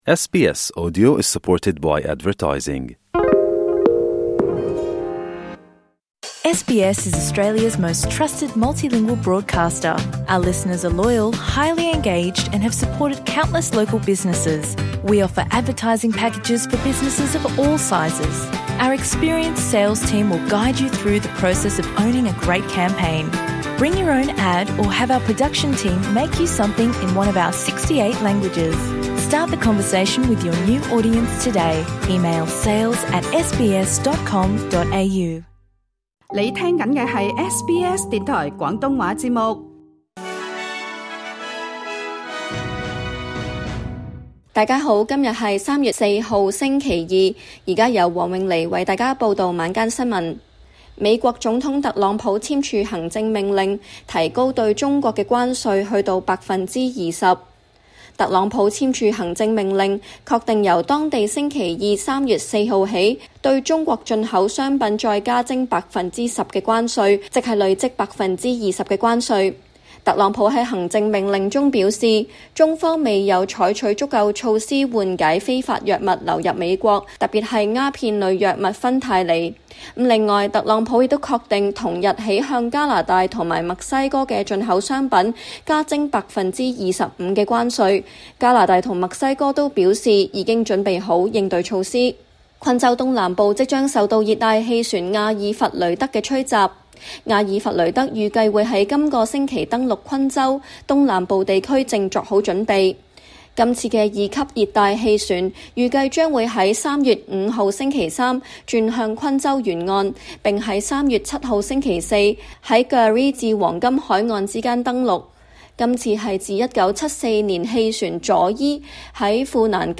請收聽本台為大家準備的每日重點新聞簡報。
SBS 晚間新聞（2025年3月4日） Play 06:03 SBS 廣東話晚間新聞 SBS廣東話節目 View Podcast Series 下載 SBS Audio 應用程式 其他收聽方法 Apple Podcasts  YouTube  Spotify  Download (5.54MB)  請收聽本台為大家準備的每日重點新聞簡報。 美國總統特朗普簽署行政命令 提高對中國關稅至20% 特朗普簽署行政命令，確定由當地星期二（3月4日）起，對中國進口商品再加徵10%關稅，即累計徵收20%關稅。